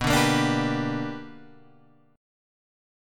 B Minor Major 7th Sharp 5th